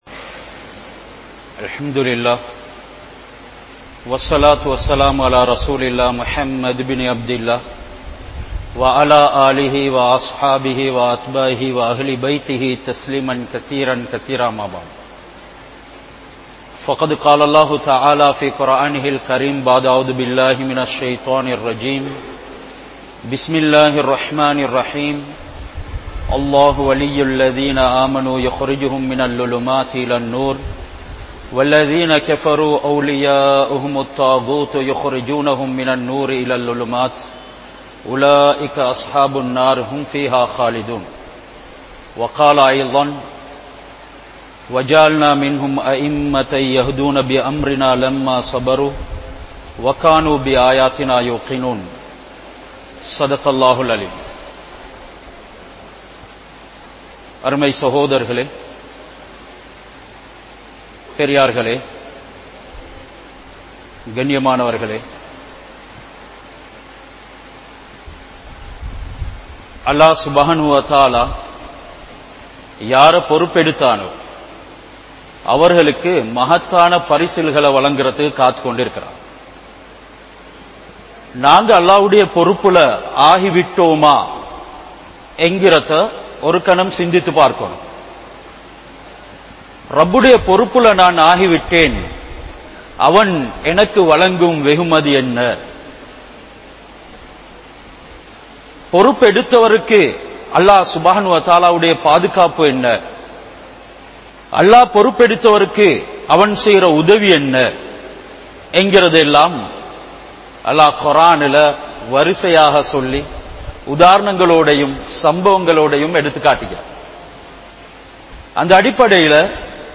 Allah`vin Sakthi (அல்லாஹ்வின் சக்தி) | Audio Bayans | All Ceylon Muslim Youth Community | Addalaichenai